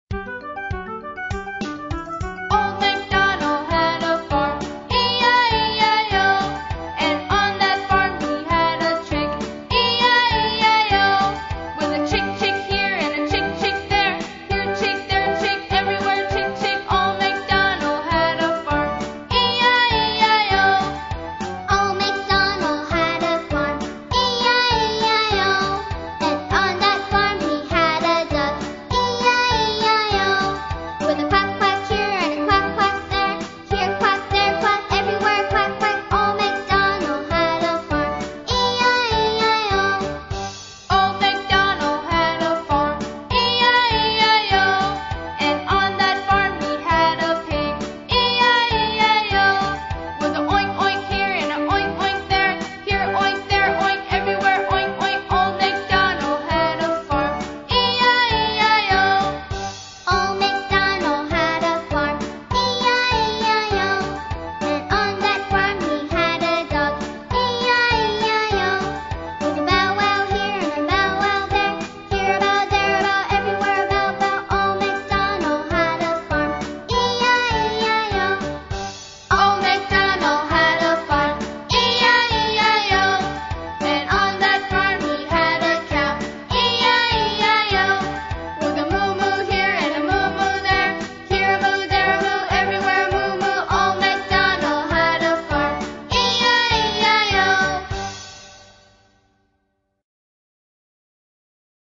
音乐节奏活泼动人的英文儿歌